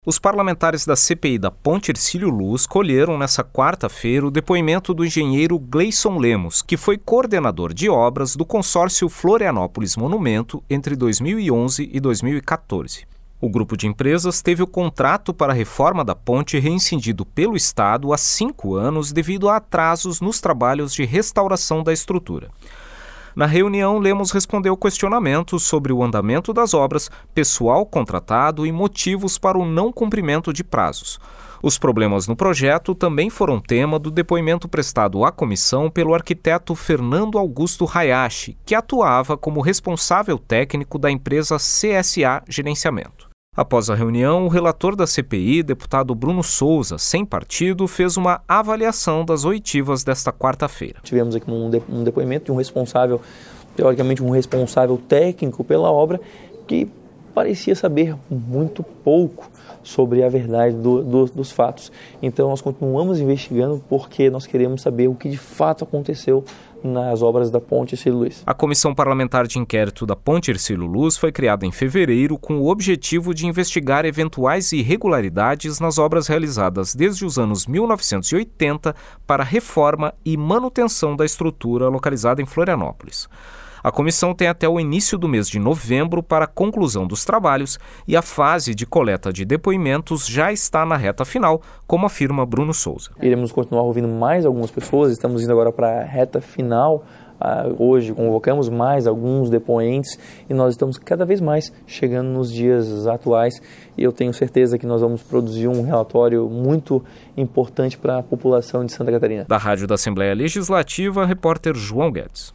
Entrevista com:
- deputado Bruno Souza (sem partido), relator da CPI da Ponte Hercílio Luz.